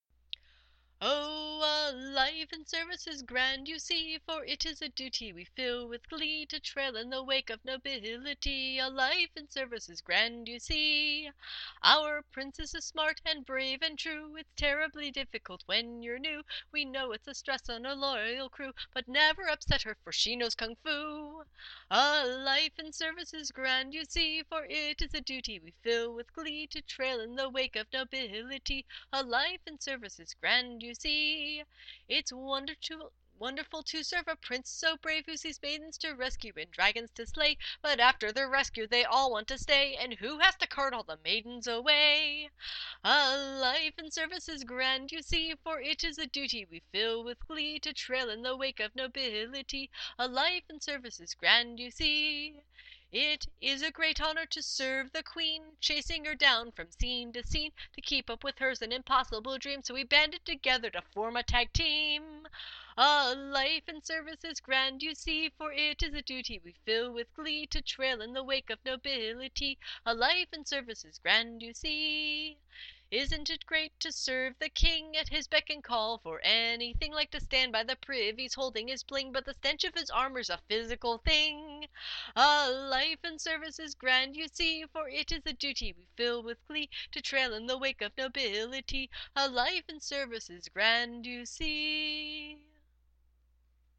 Here’s a cute little ditty that I came up with while retaining for royalty at the most recent Pennsic.
It’s to the tune of “The Irish Jig” or “The Night Ramble”, a late period song by the ever-popular author, Anonymous.